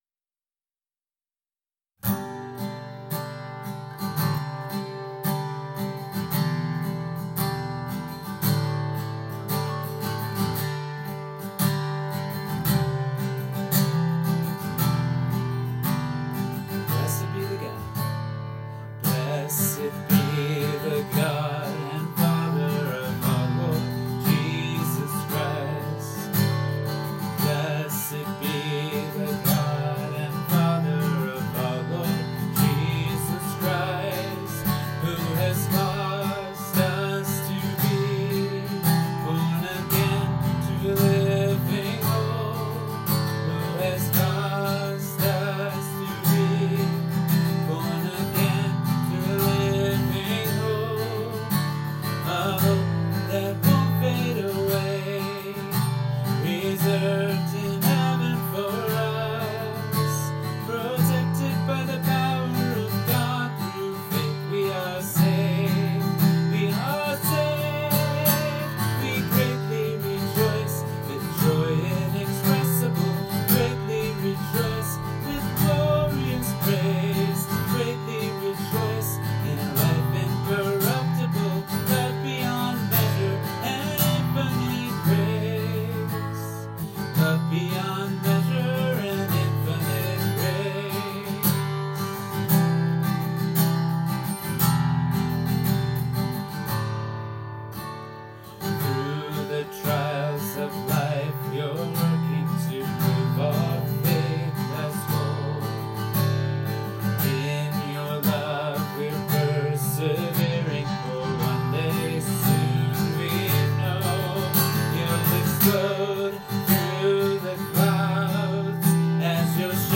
Song